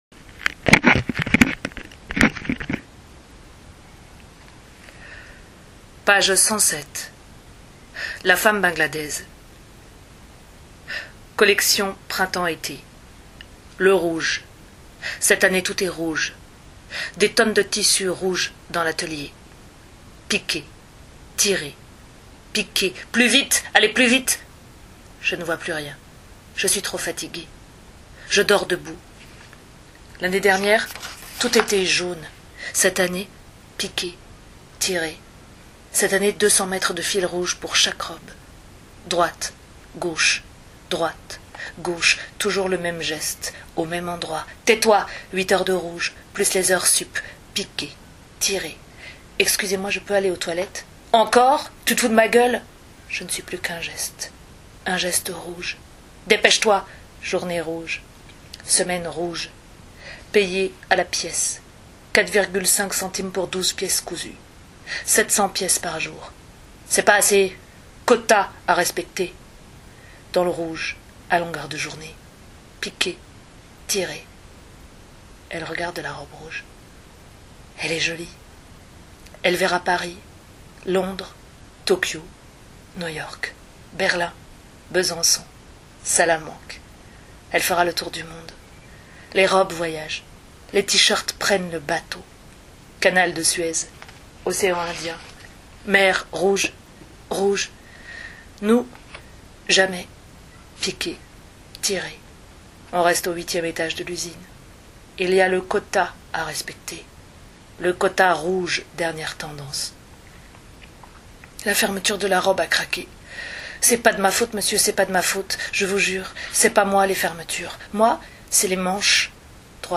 Comme convenu, je vous ai enregistré deux extraits de Comment on freine ?